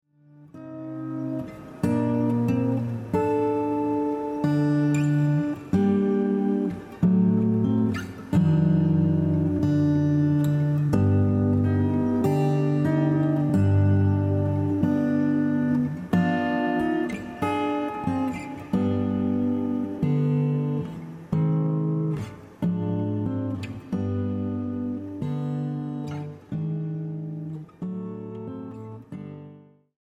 G minor